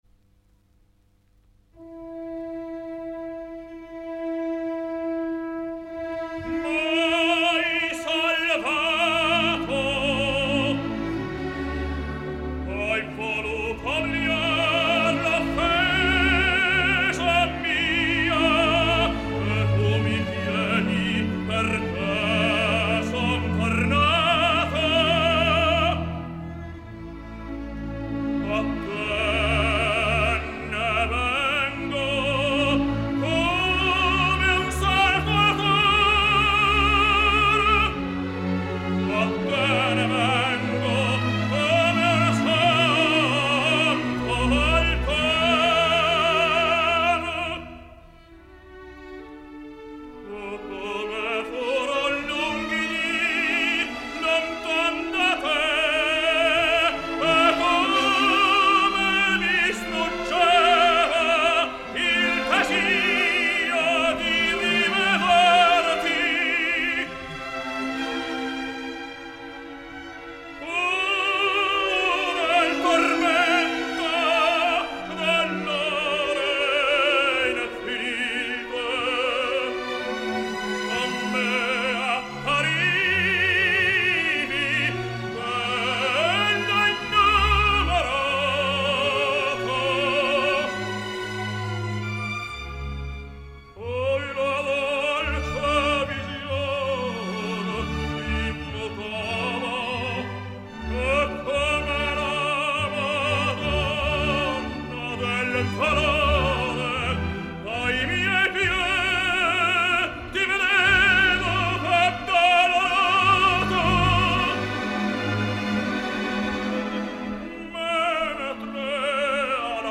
El més sorprenent per a mi, tot i que hi ha programes que identifiquen els fragments d’àudio, és que la primera pista tingués 20 respostes que van identificar a Mario Del Monaco cantant  “Oh, Come Furon Lunghi” de La Wally i que per tant van saber que aquesta òpera es va estrenar l’any 1892.